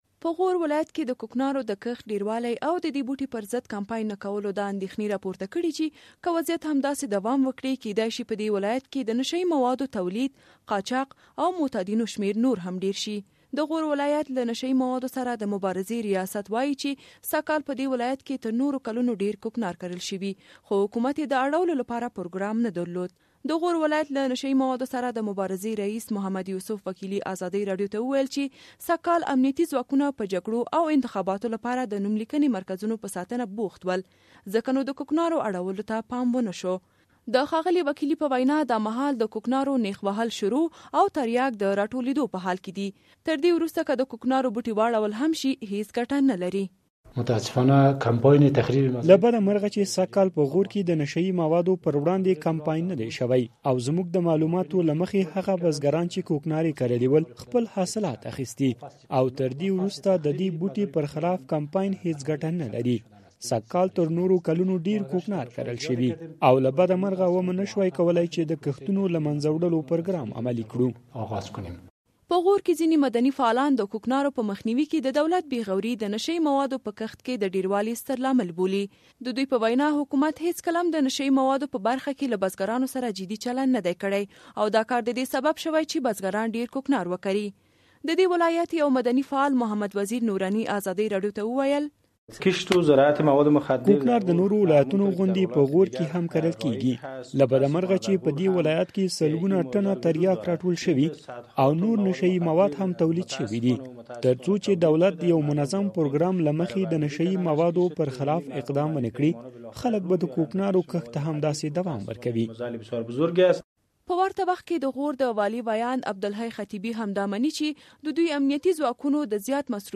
د غور راپور